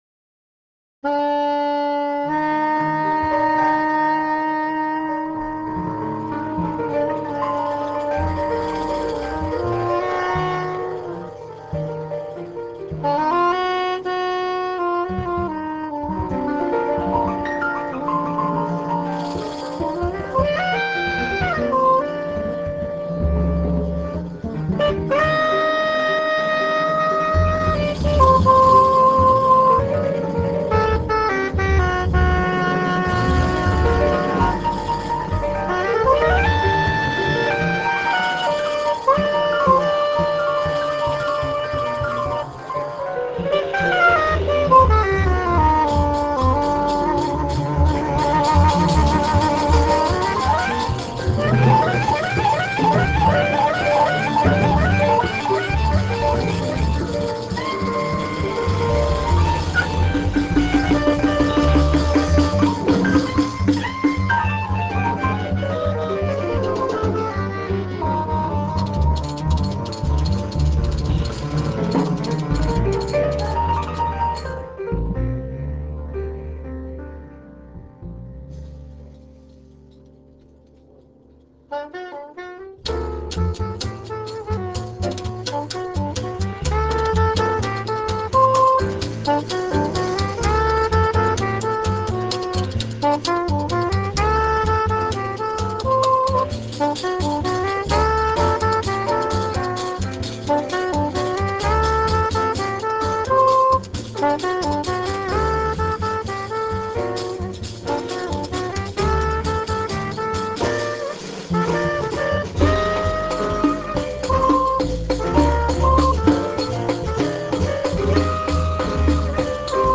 soprano saxophone
piano
bass
drums
tambourine